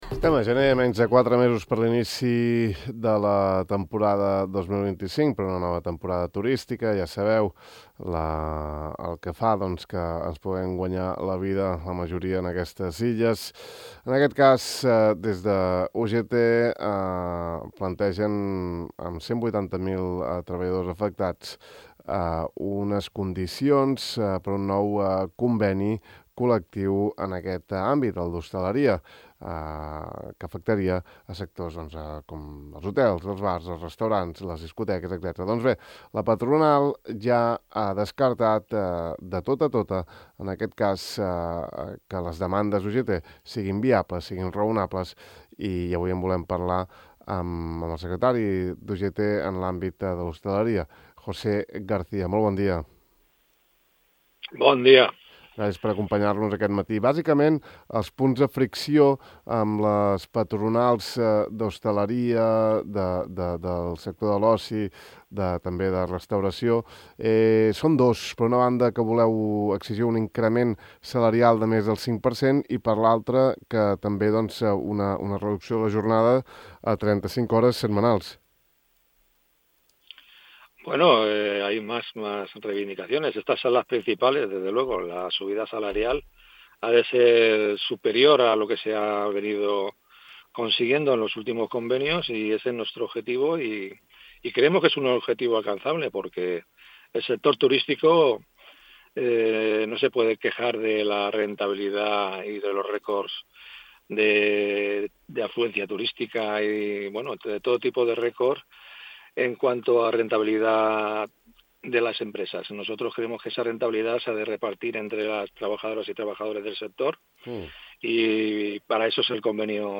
D’aquesta demanda n’hem parlat avui amb el vicepresident del Consell d’Eivissa, Mariano Juan Colomar: